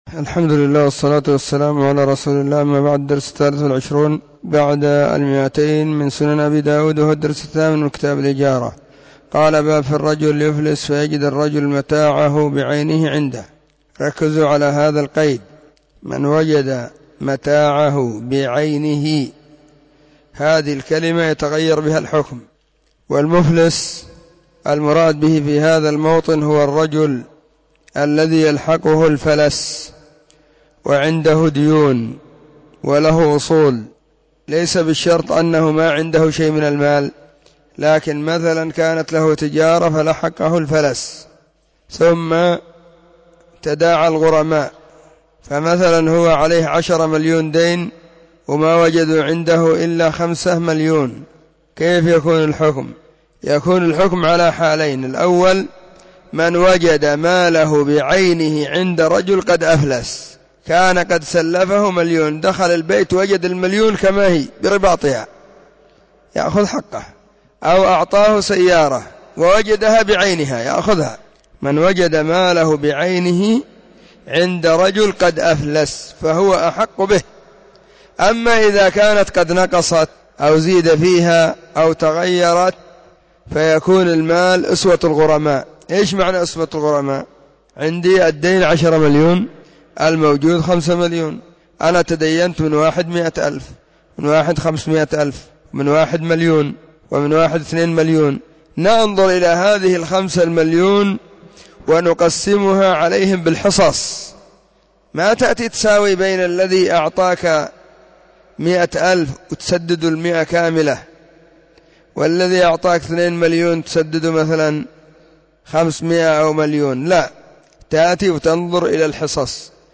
📢 مسجد الصحابة بالغيضة, المهرة، اليمن حرسها الله.